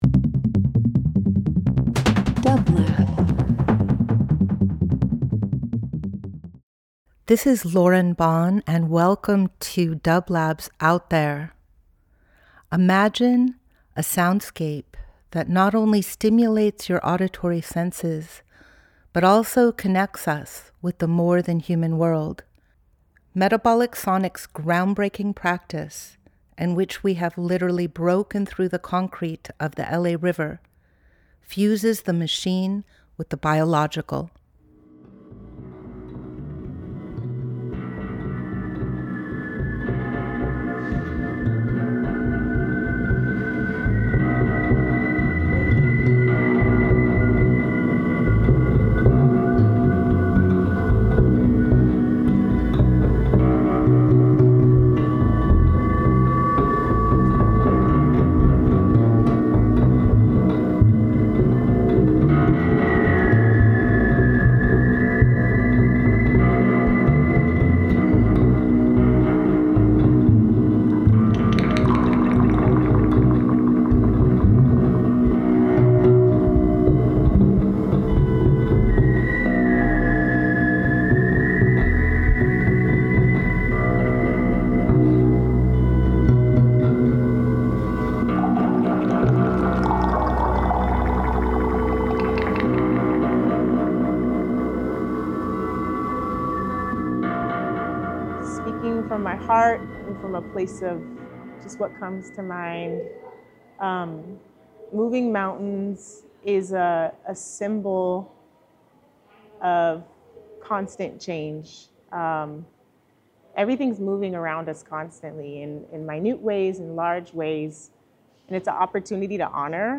Each week we present field recordings that will transport you through the power of sound.
Out There ~ a field recording program